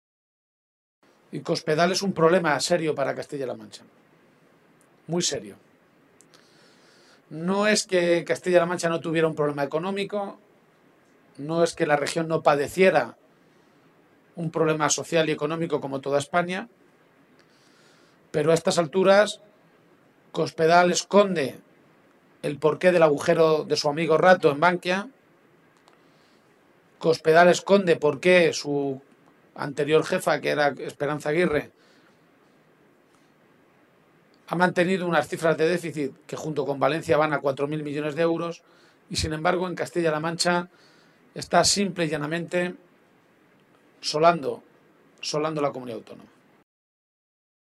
Poco antes del inicio de esa reunión, el secretario general regional, Emiliano García-Page, ha comparecido ante los medios de comunicación para anunciar la aprobación de una resolución que ha llamado “La Resolución de Cuenca”.